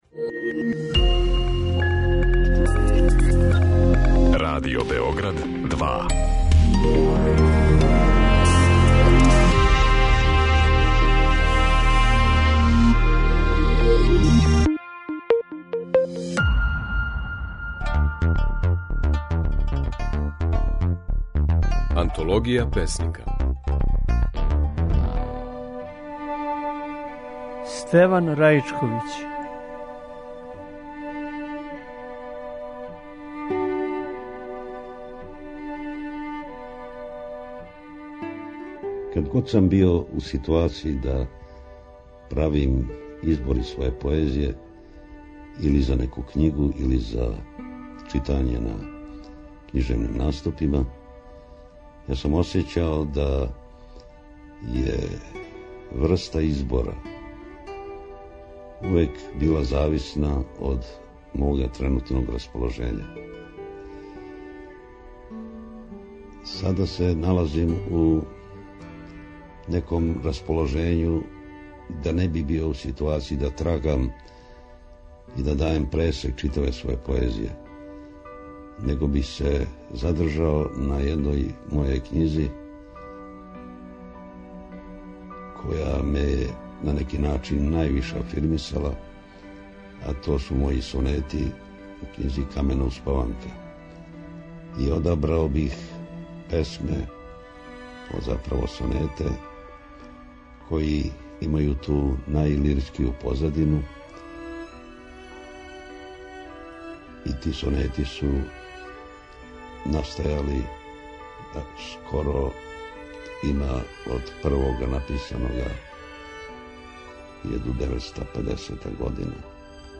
У Антологији песника можете чути како је он казивао своје стихове.